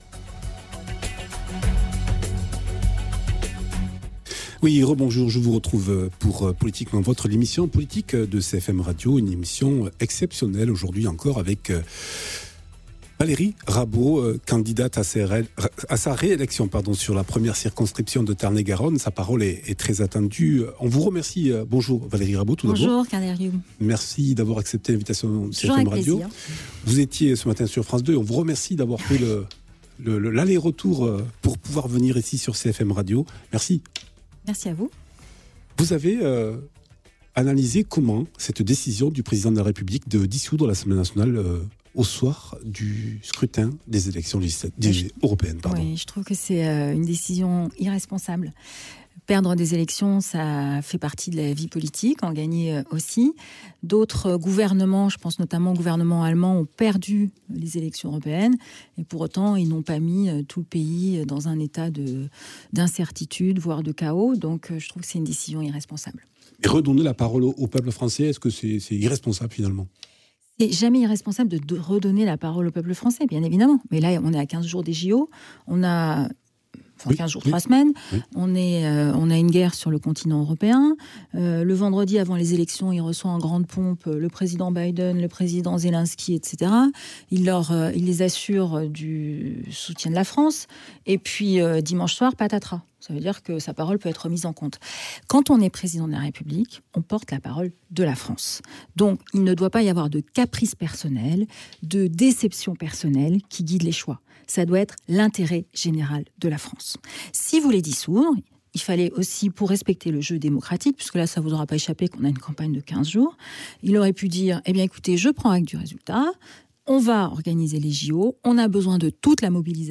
Invité(s) : Valérie Rabault, députée sortant de la 1ère circonscription du Tarn-et-Garonne.